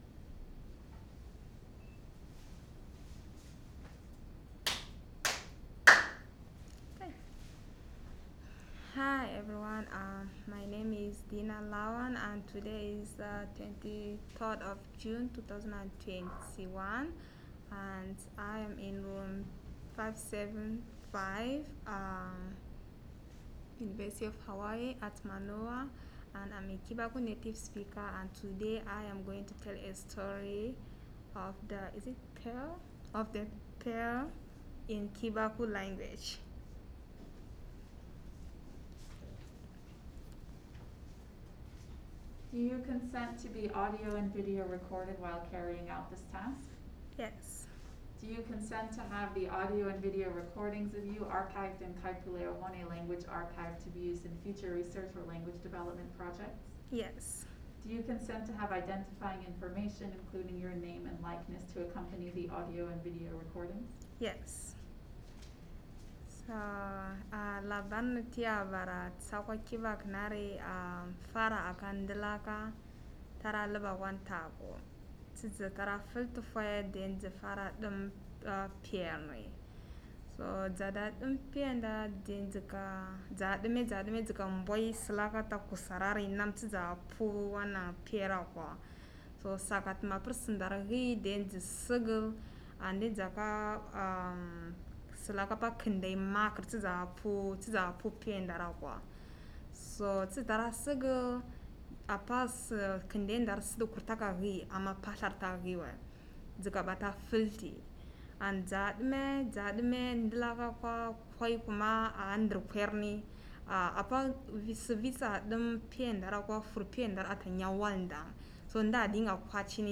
dc.description.regionChibok, Borno, State, Nigeria; recorded made in Honolulu, Hawaii
dc.formatdigital wav file recorded at 44.1 kHz/16 bit on H4N zoom recorder with an audio-technica AT8033 cardiod condenser microphone; video file (.mp4) recorded on a panasonic HC-V770 video camera with RODE video mic pro